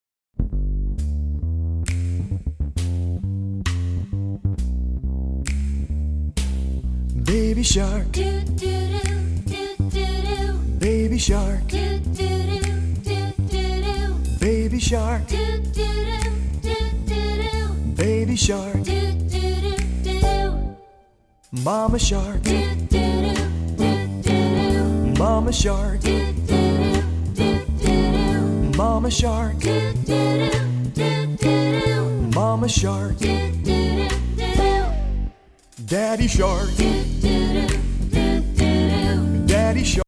Instrumentals (with lead or backing vocals)